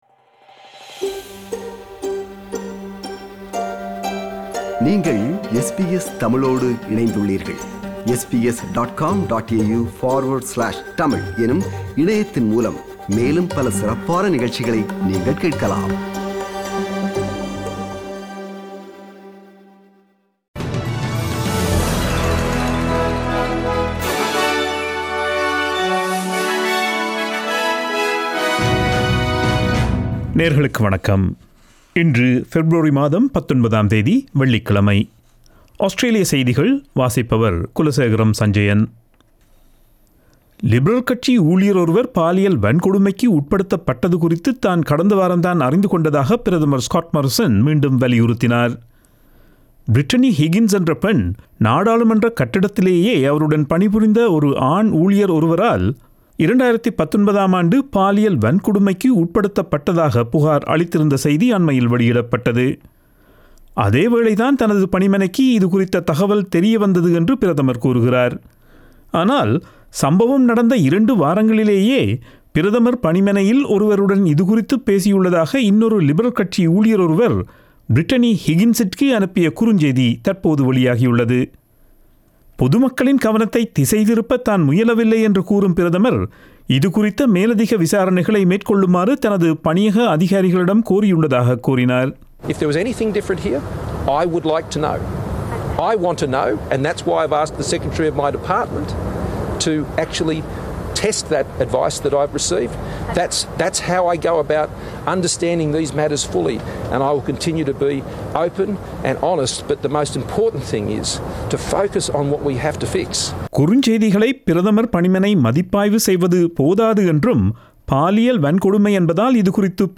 Australian news bulletin for Friday 19 February 2021.